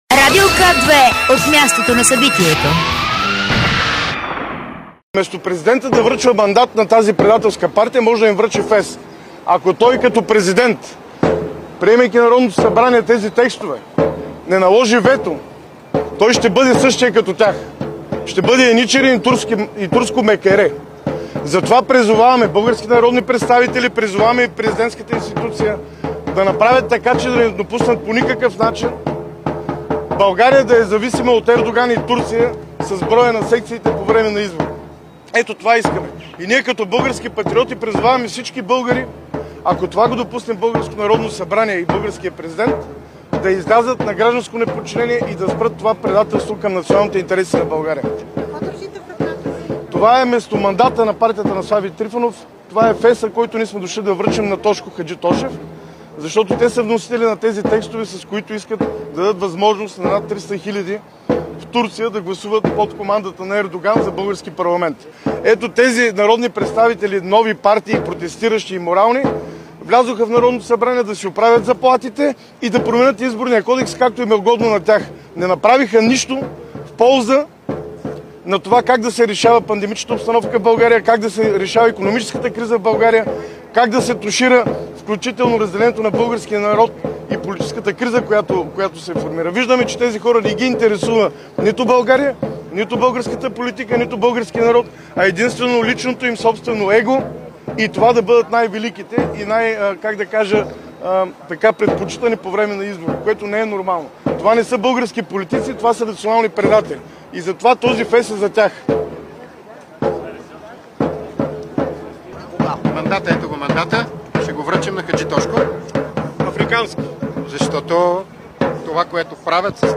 Директно от мястото на събитието